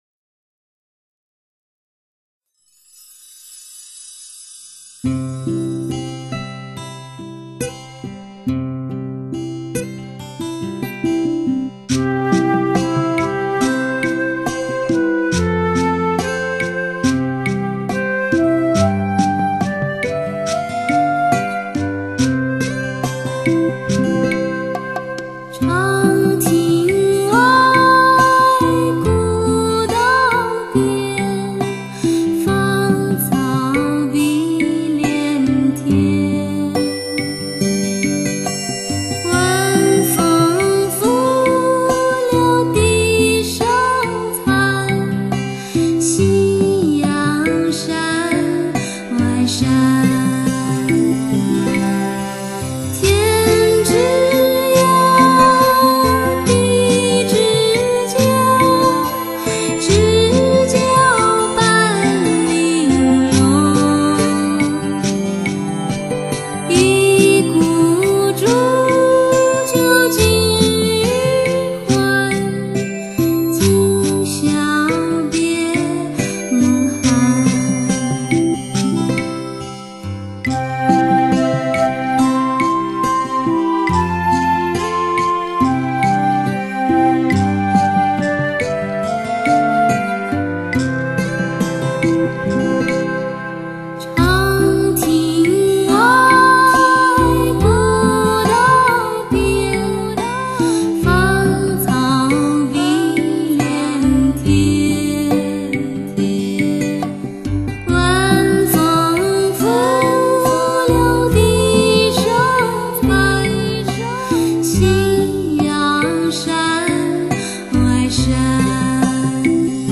既然是正版六声道WAV转换  音效也就不俗
女声